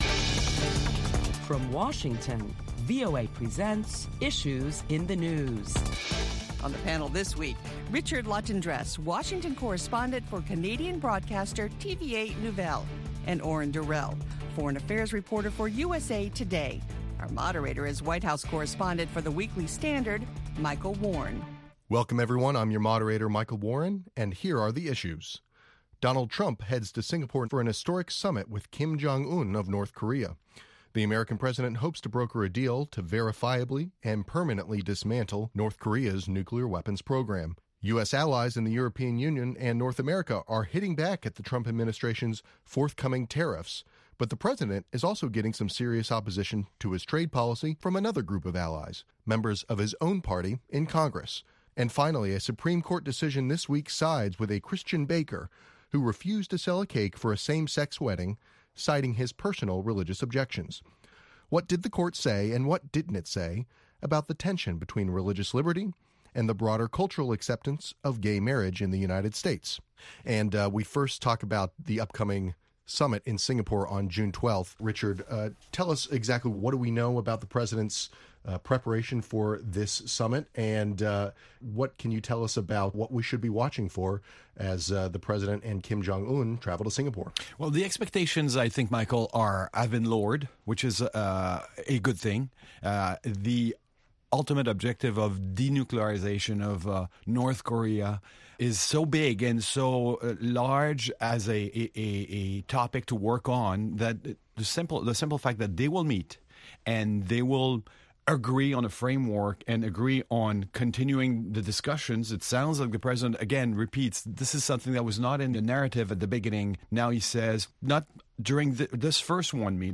Topping the headlines – President Trump’s upcoming summit with North Korean leader Kim Jong Un. Listen Saturday and Sunday for this and other top stories on Issues in the News.